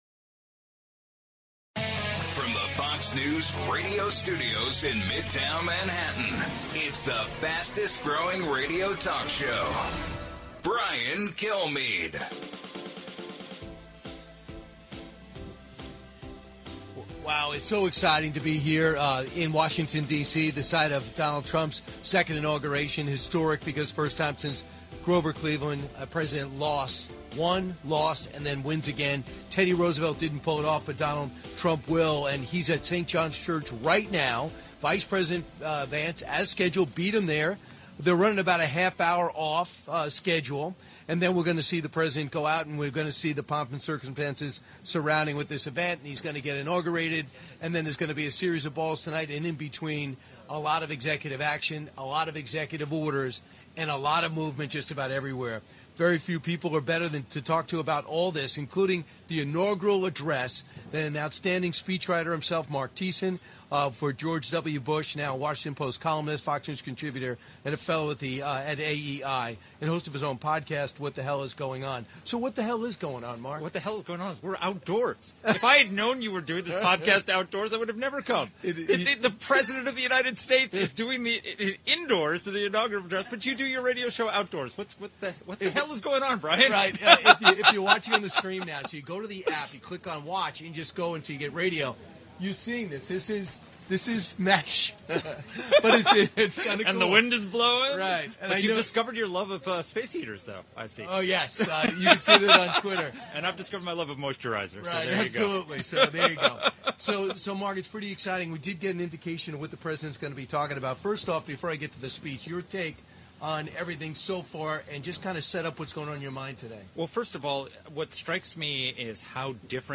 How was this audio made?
LIVE From Washington, D.C. for the Inauguration of Donald J. Trump